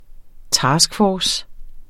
Udtale [ ˈtɑːsgˌfɒːs ]